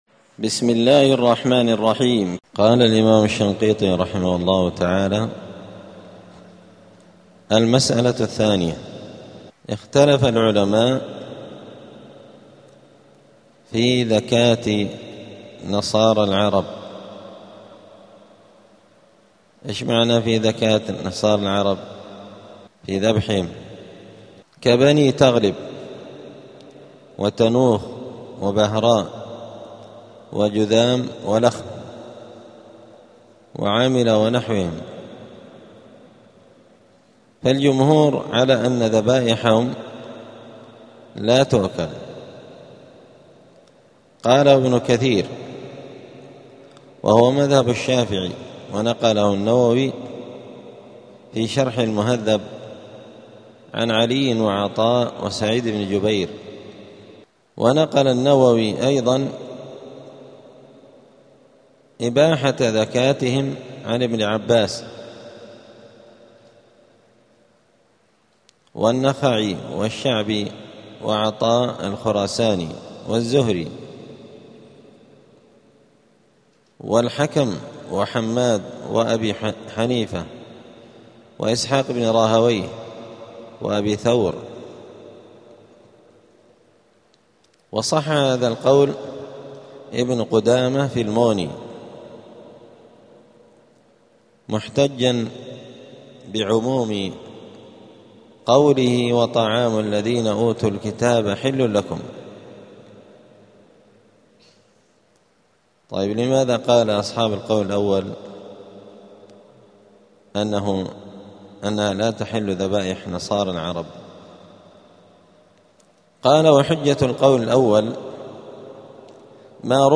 *الدرس الثاني الثلاثون (32) {سورة المائدة}.*